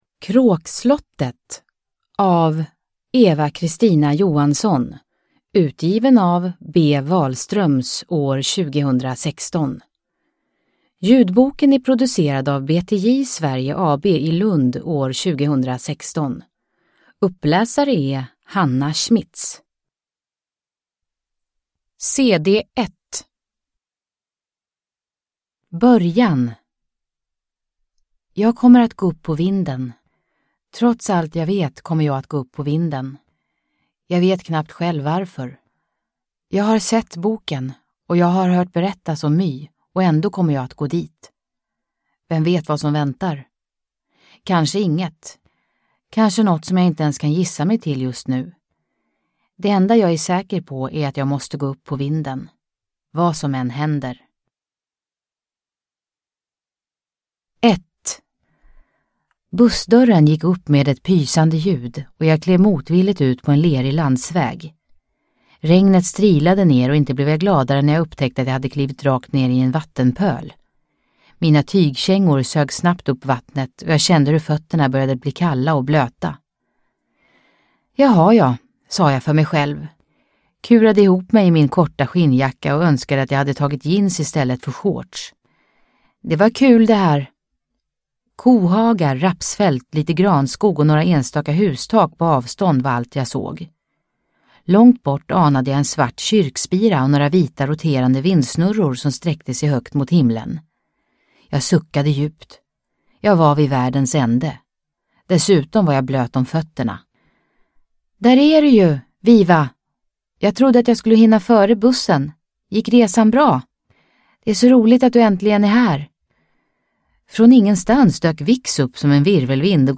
Kråkslottet – Ljudbok